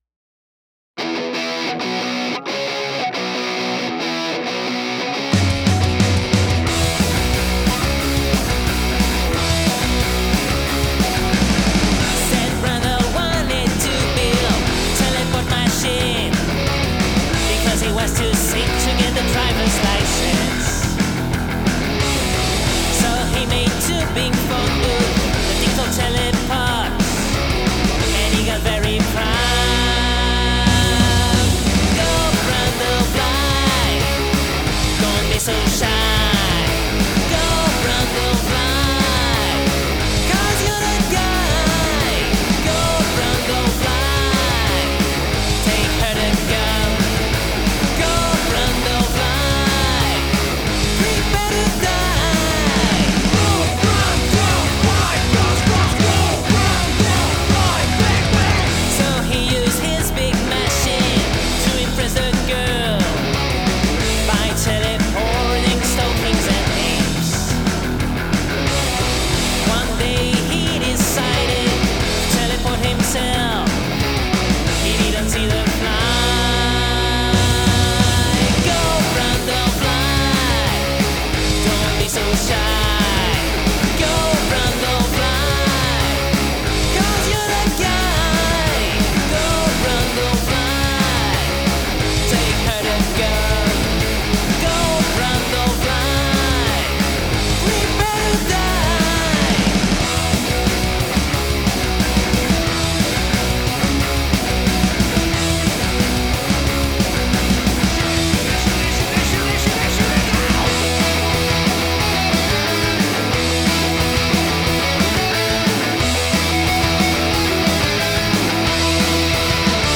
Rock > Punk